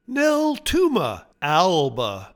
Pronounciation:
Nel-TOO-ma AL-ba